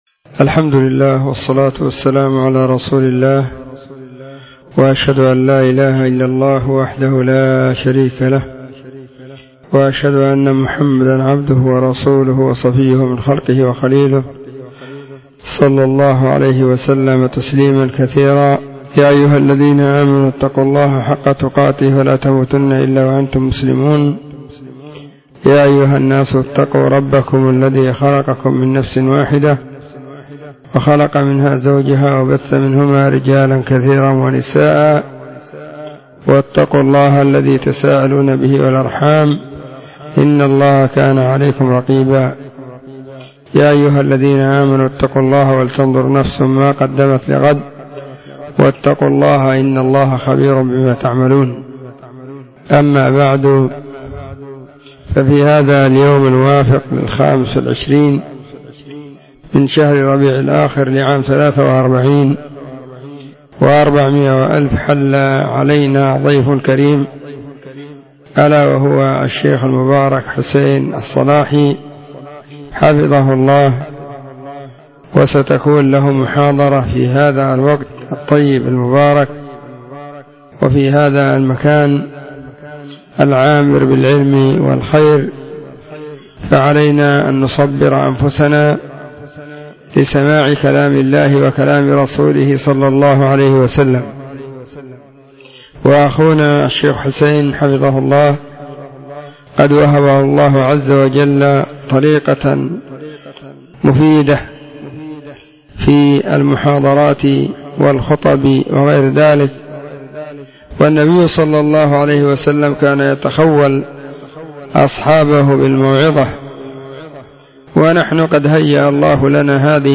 📢 مسجد – الصحابة – بالغيضة – المهرة، اليمن حرسها الله.